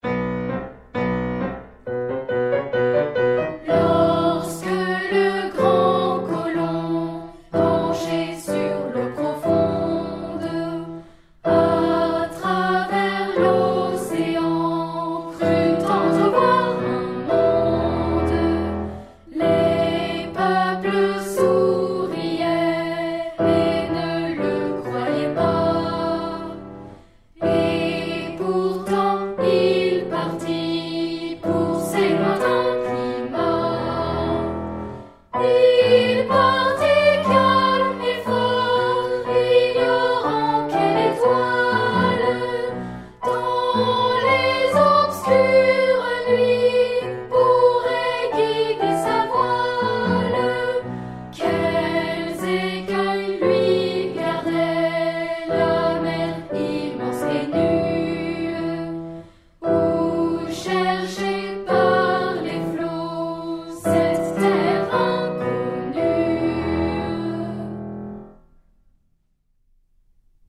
Effectif :  UnissonVoix égales
Enregistrement piano et voix
Arrangement Cyrille Lehn pour voix seule et accompagnement piano
nouveau-monde-4e-mvt-piano-chant.mp3